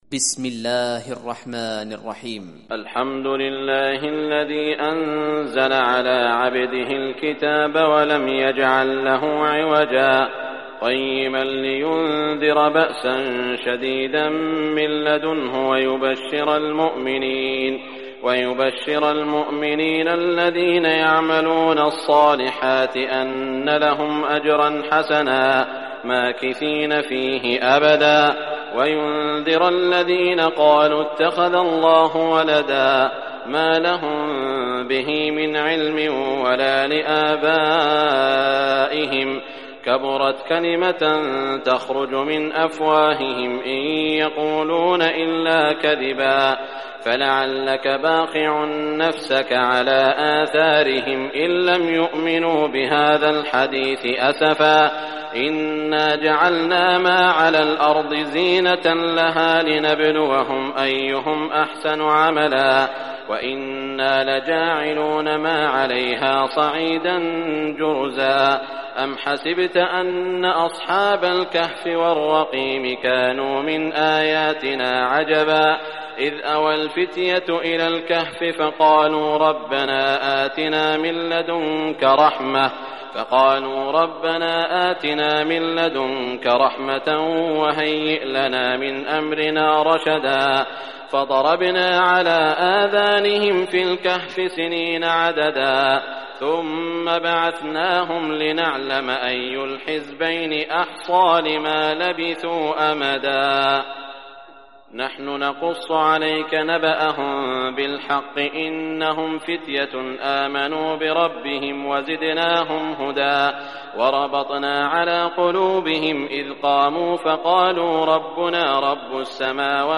Surah Al-Kahf Recitation by Sheikh Shuraim
Surah Al-Kahf, listen or play online mp3 tilawat / recitation in Arabic in the beautiful voice of Sheikh Saud al Shuraim.